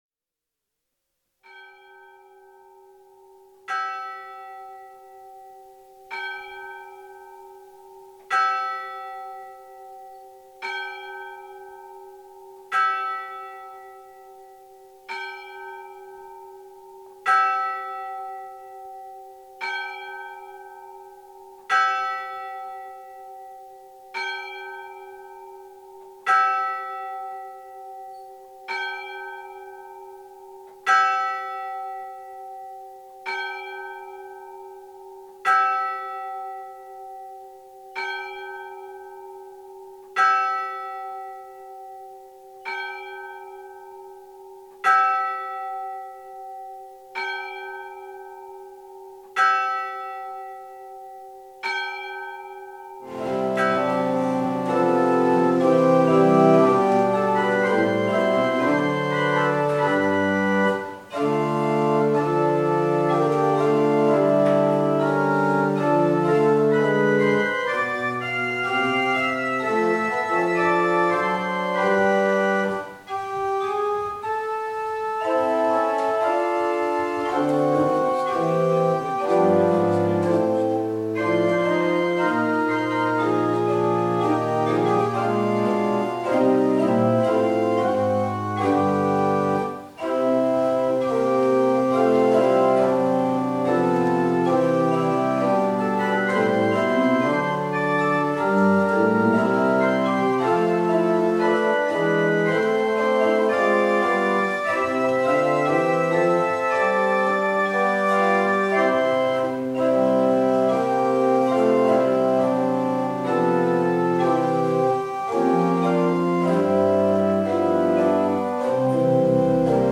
Jumalateenistus 28. september 2025